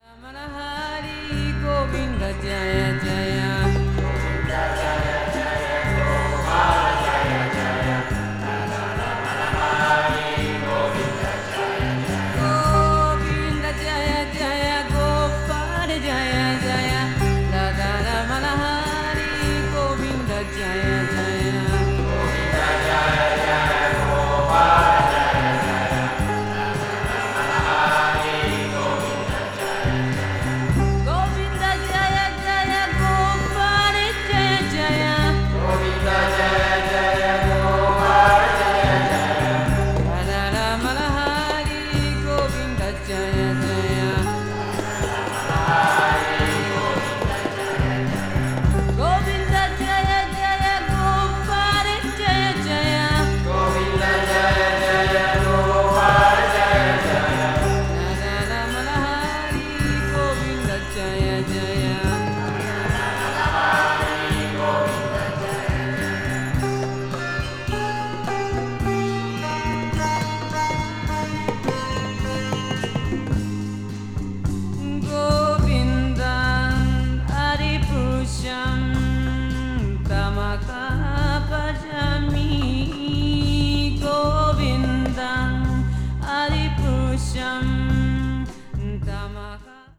media : EX+/EX+(わずかにチリノイズが入る箇所あり)
blues rock   folk rock   jazz vocal   psychedelic rock